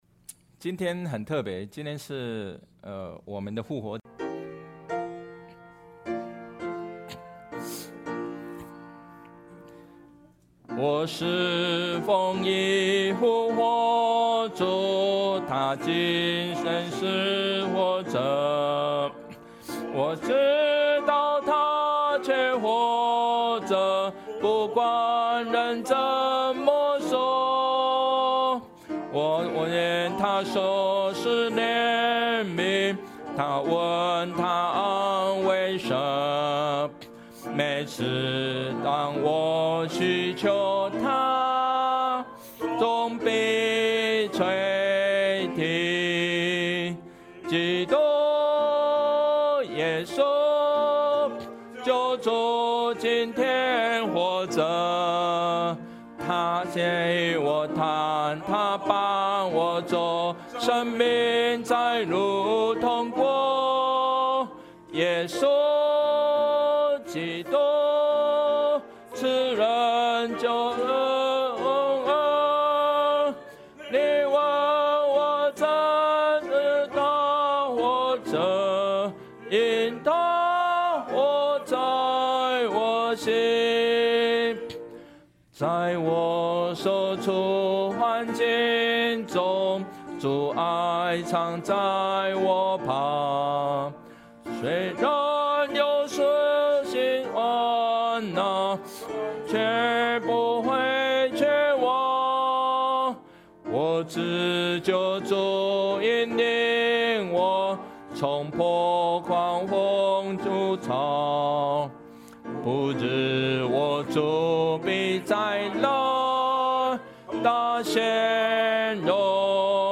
Passage: 1 Thessalonians 4:13-18 Service Type: 主日证道 Download Files Notes « 绝境中的力量 不可能的任务 » Submit a Comment Cancel reply Your email address will not be published.